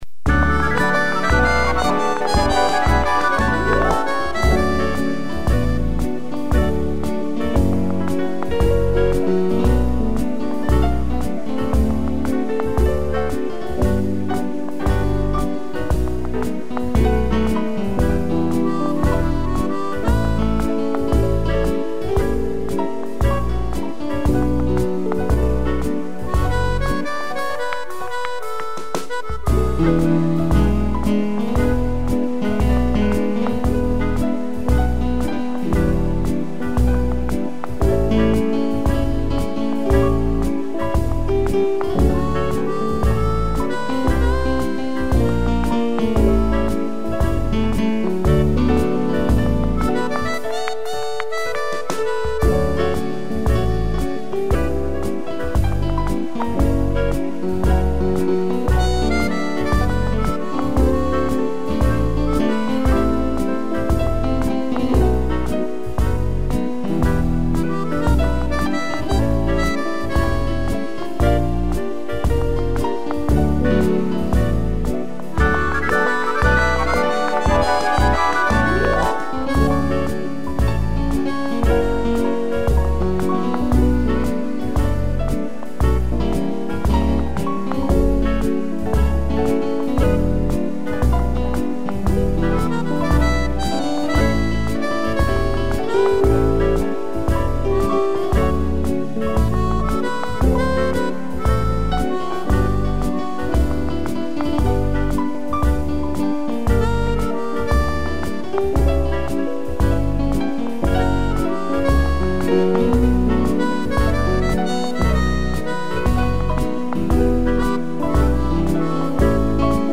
piano e flauta
instrumental